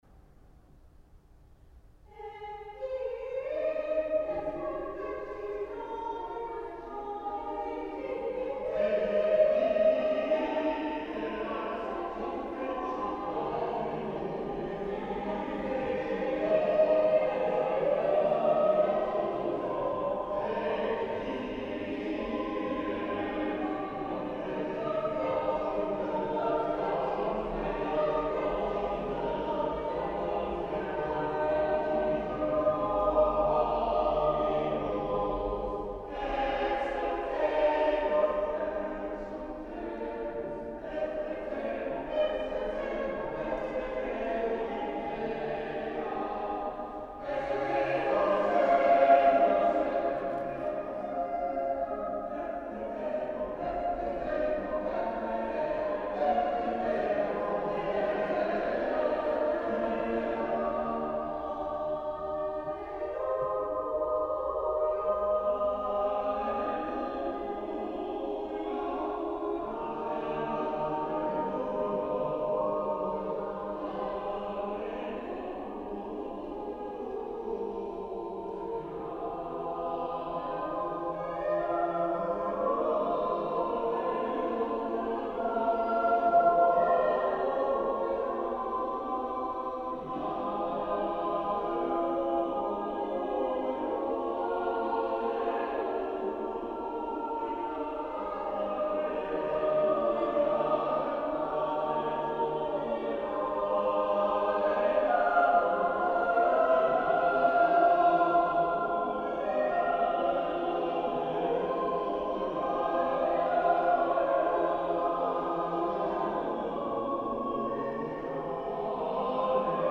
Genre: Choral.
01-Evensong-Haec-Dies.mp3